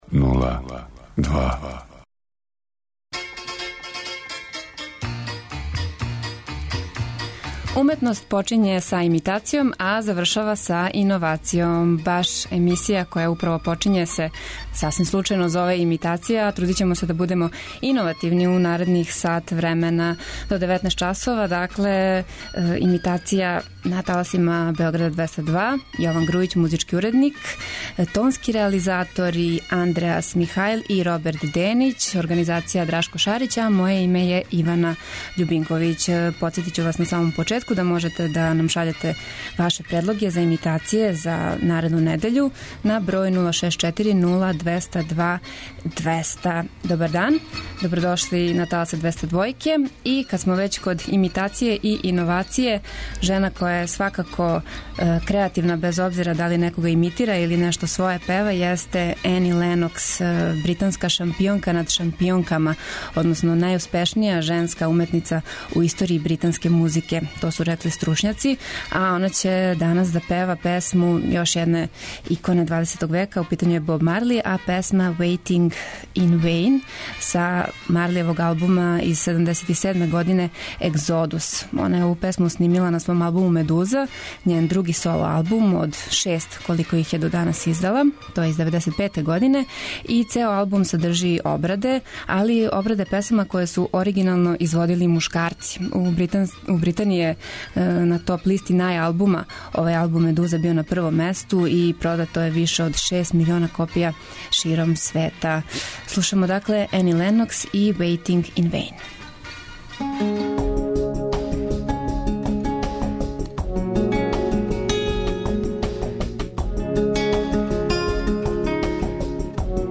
Имитација је емисија у којој се емитују обраде познатих хитова домаће и иностране музике.